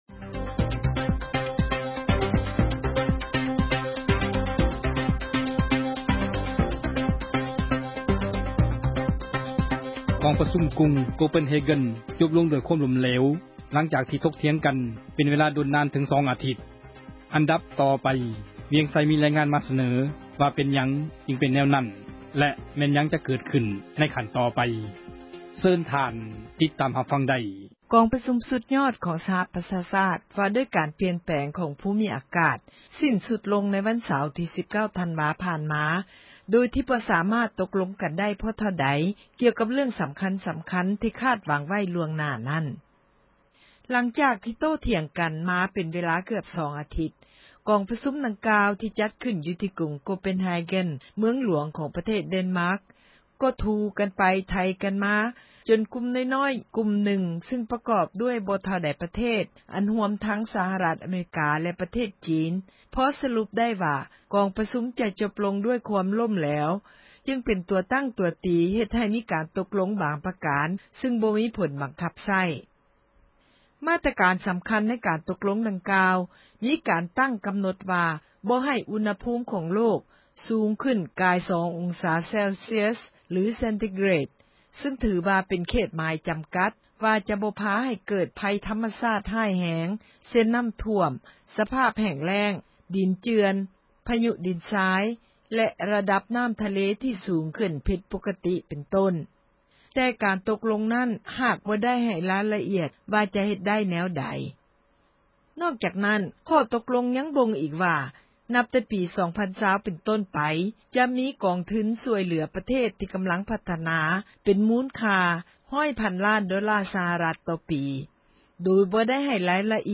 ມີຣາຍງານມາສເນີ